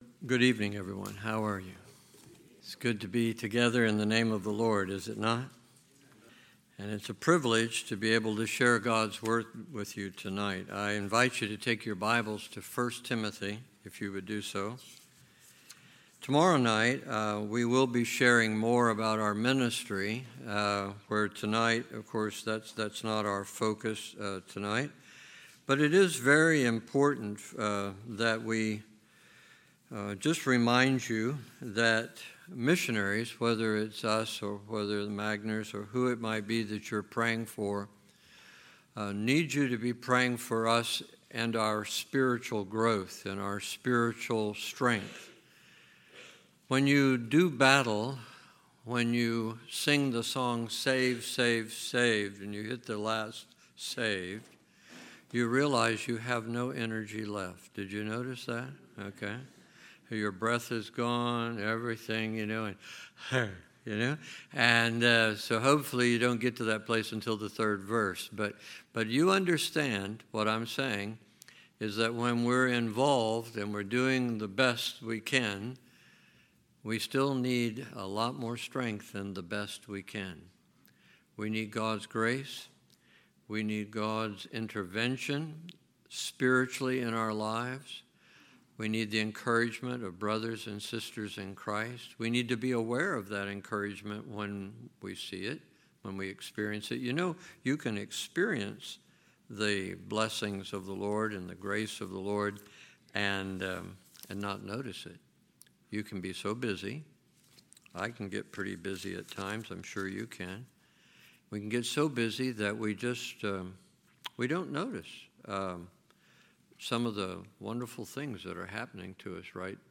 2022 Missions Conference , Sermons